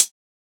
edm-hihat-47.wav